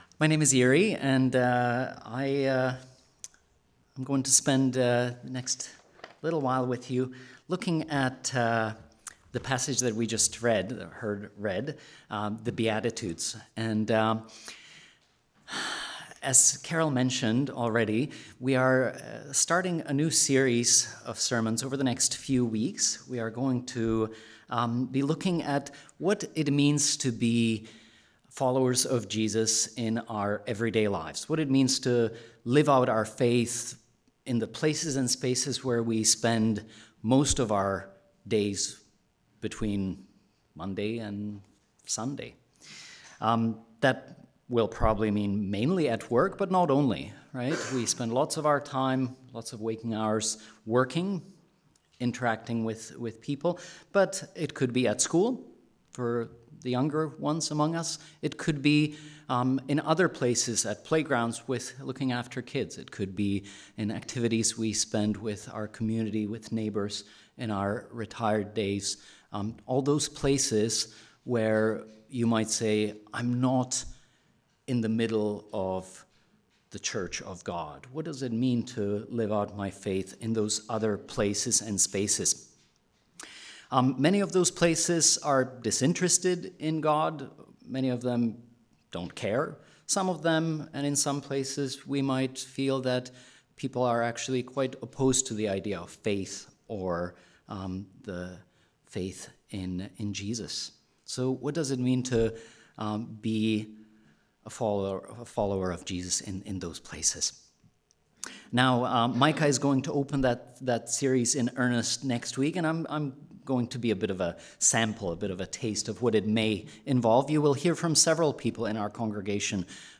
Sermons | Living Hope Church